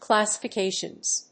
/ˌklæsʌfʌˈkeʃʌnz(米国英語), ˌklæsʌfʌˈkeɪʃʌnz(英国英語)/